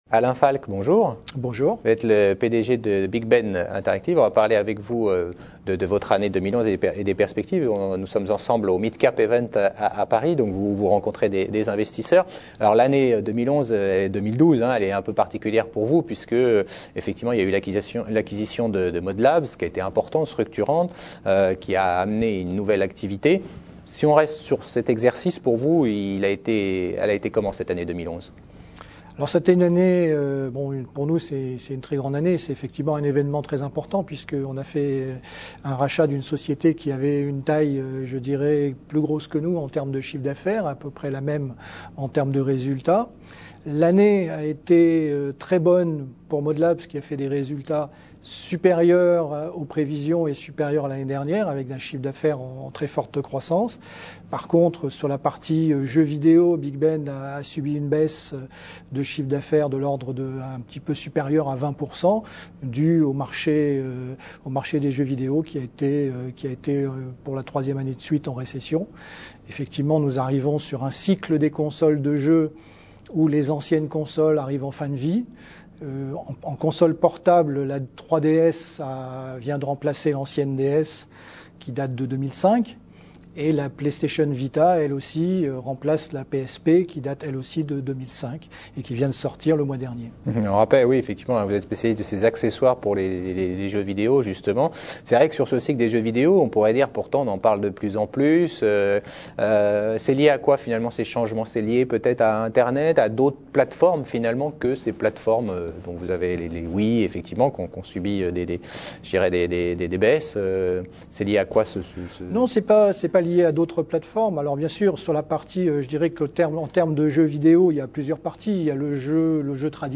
Smallcap Event à Paris.